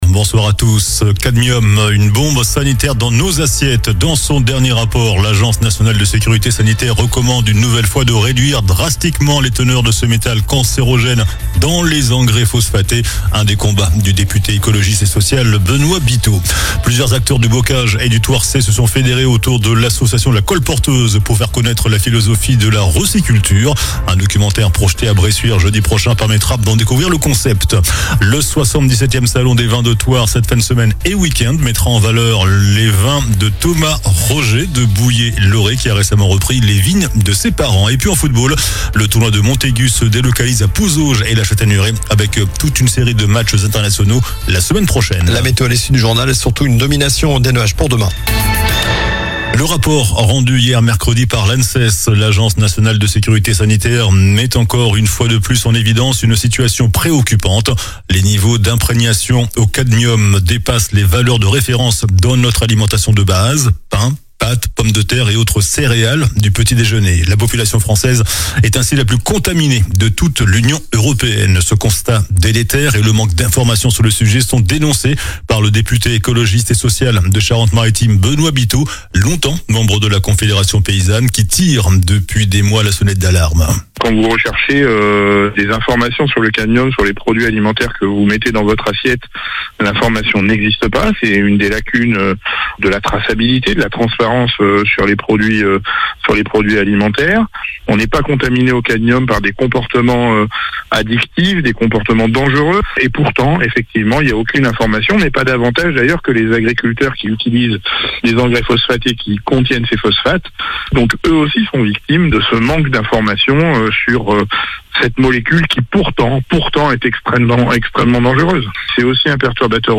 JOURNAL DU JEUDI 26 MARS ( SOIR )